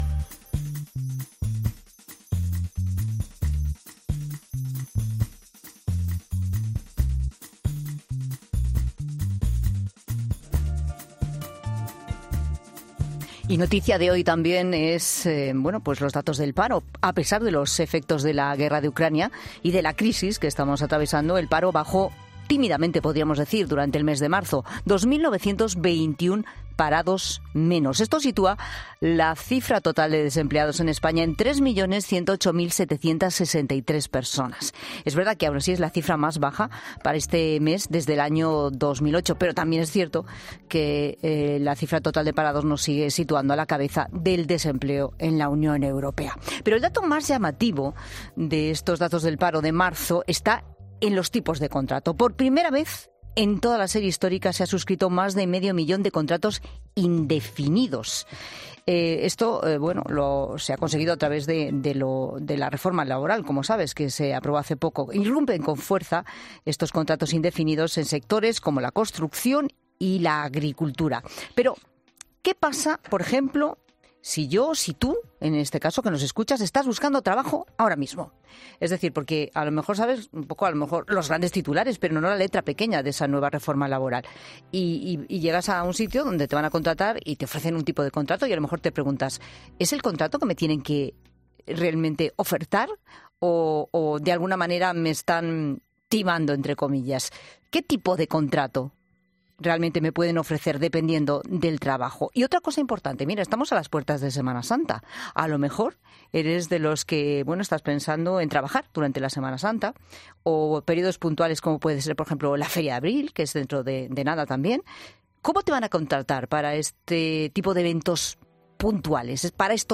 Lo hablamos en la sección de 'Economía de Bolsillo' con el economista Fernando Trías de Bes.